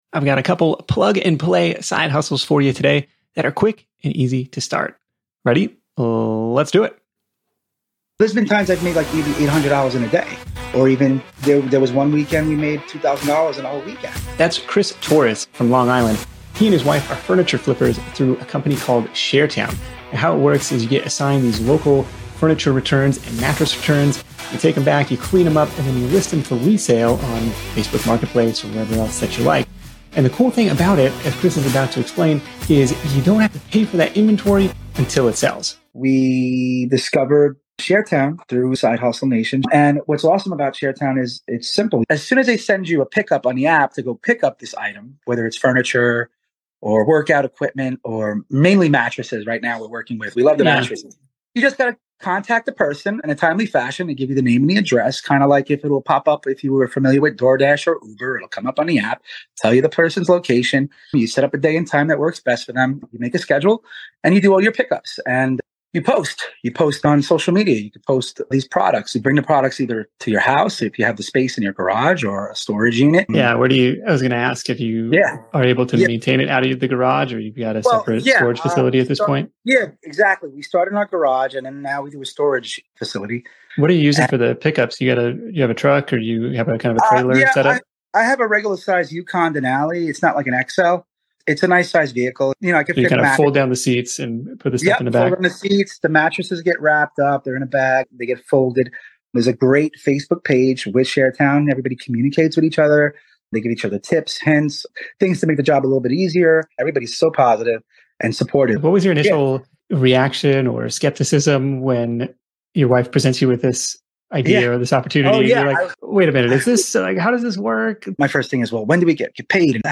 For the full details, check out: Sharetown Review: Inside the Mattress Pickup and Resale Side Hustle For our second side hustle, we talk with a veteran mystery shopper.